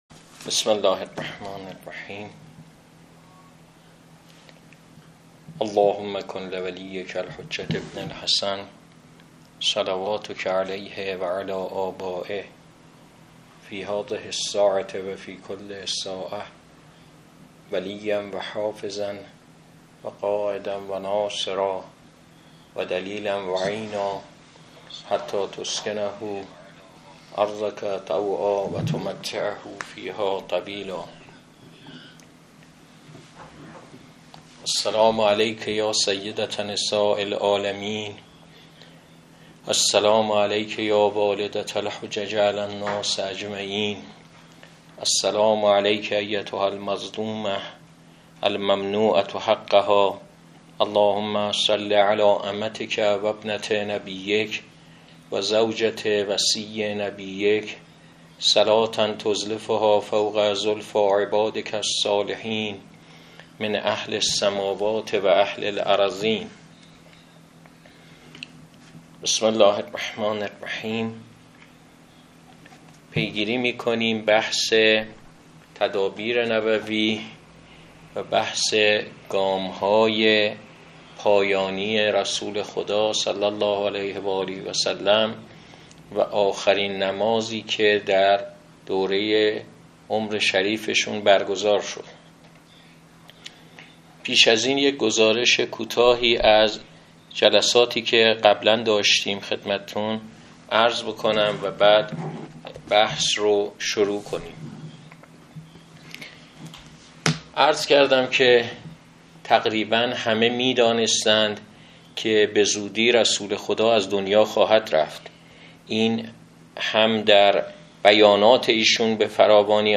پیوند سخنرانی
سخنرانی گردهمایی 22 آبان‌ماه 1404 تحت عنوان: